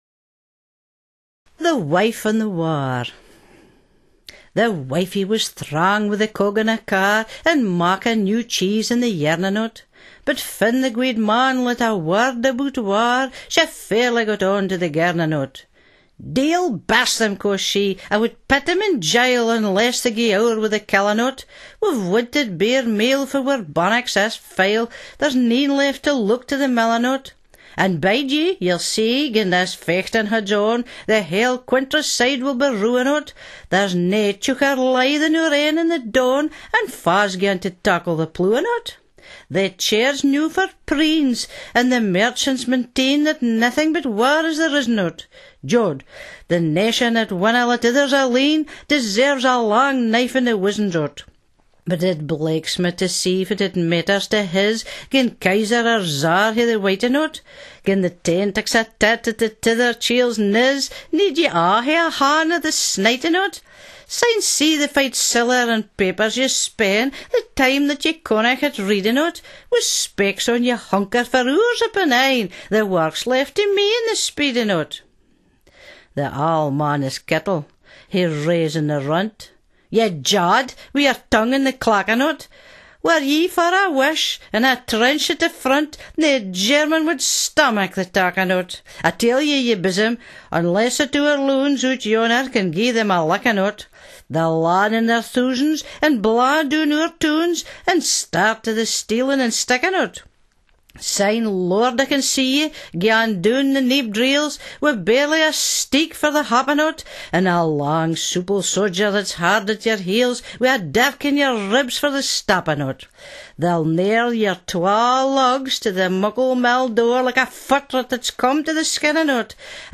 Modern readings of Murray's poems from the CD At Hame with Charles Murray (1:26) Aiberdeen Awa!